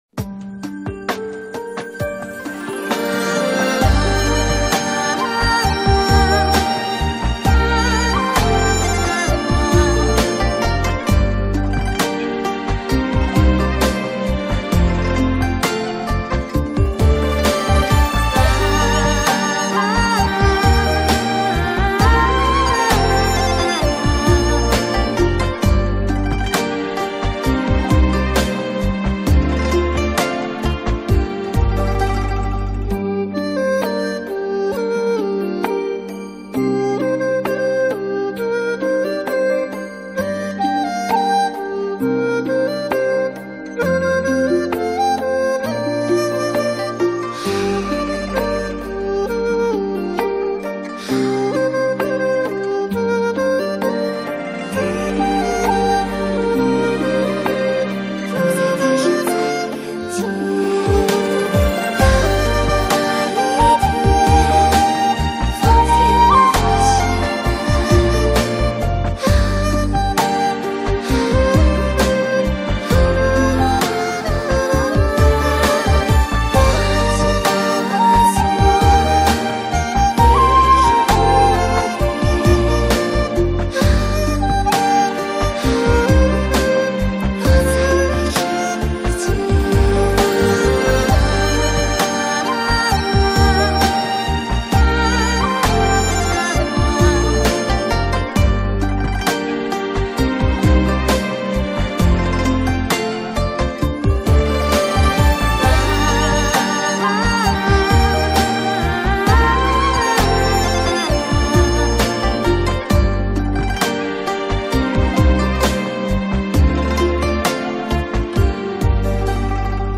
giai điệu ngọt ngào và lãng mạn.
bản nhạc không lời chất lượng cao